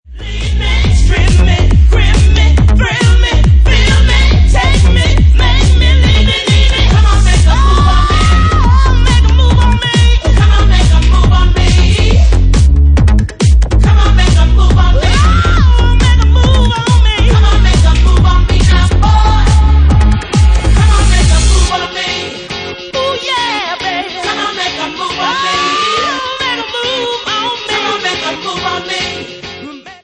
Genre:Bassline House